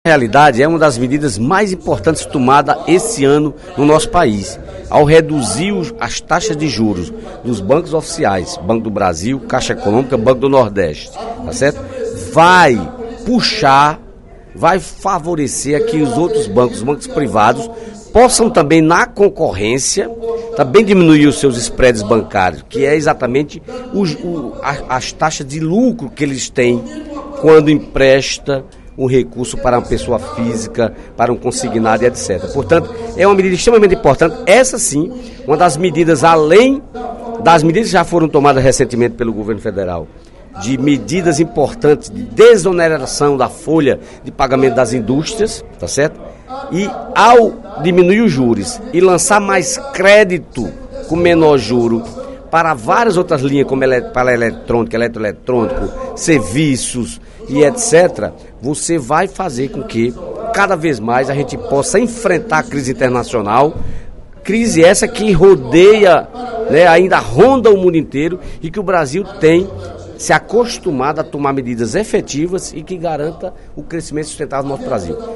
O deputado Dedé Teixeira (PT) comentou, durante pronunciamento na sessão plenária desta quarta-feira (11/04), que a questão dos juros bancários está sendo enfrentada com sucesso pelo Governo Dilma e já rende benefícios para a população.